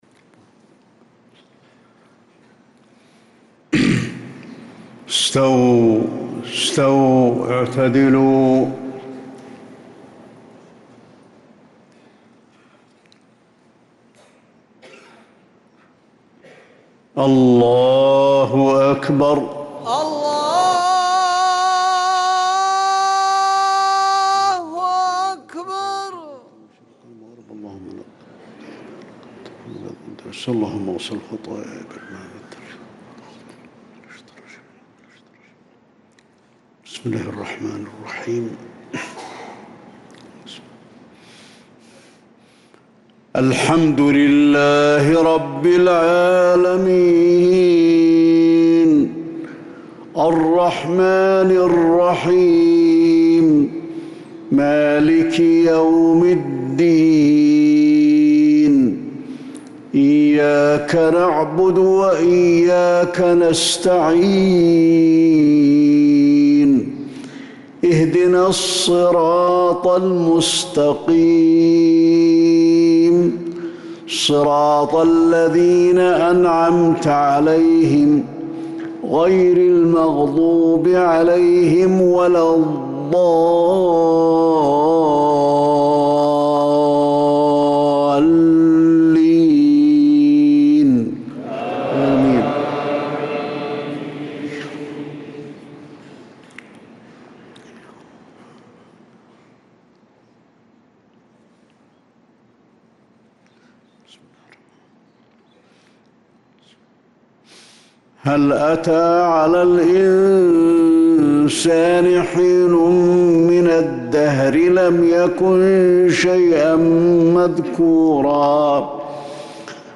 صلاة الفجر للقارئ علي الحذيفي 20 ذو القعدة 1445 هـ
تِلَاوَات الْحَرَمَيْن .